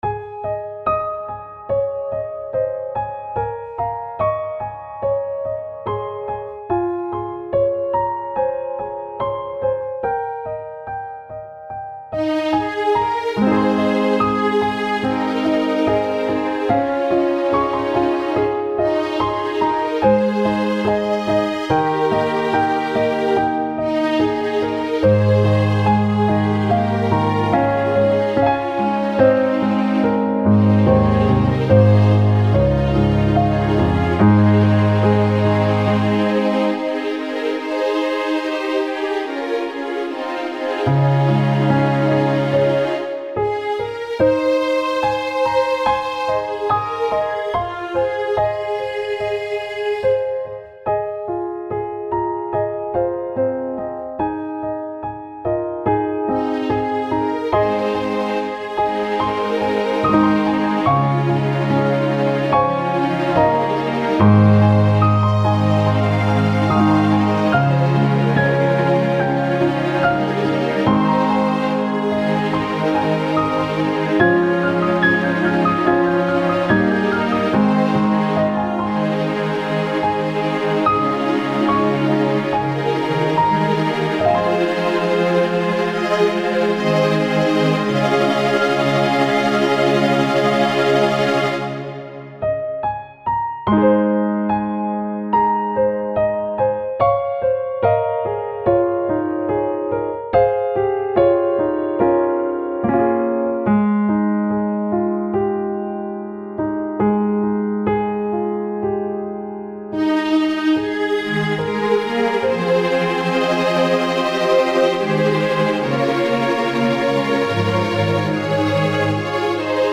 Listen to a Midi Realization